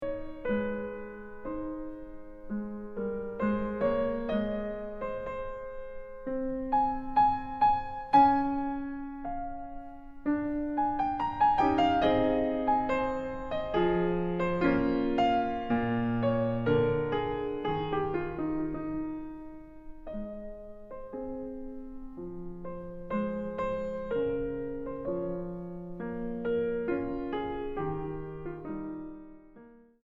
Música mexicana para piano de los siglos XX y XXI.
piano